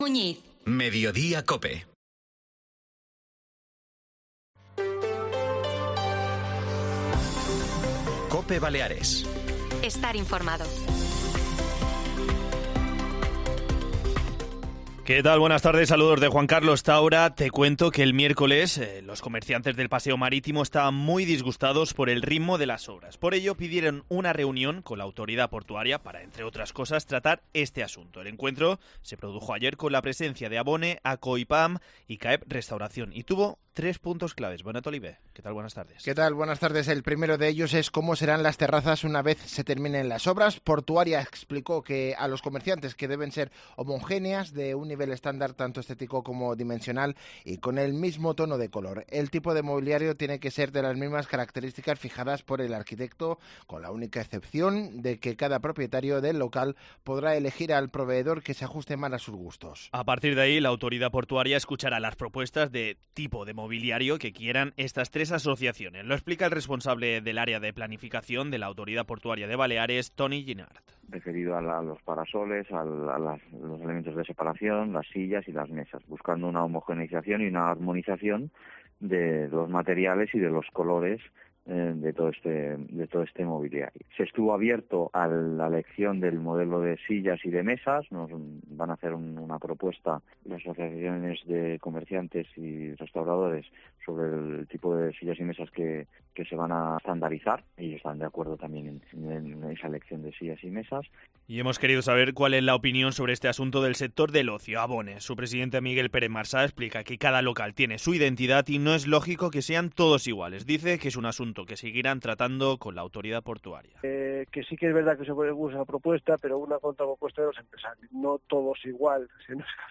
Informativo del mediodía